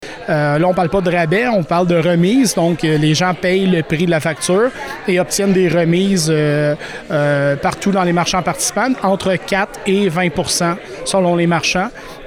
Cette initiative, qui est une première au Québec, a été annoncée en conférence de presse mercredi.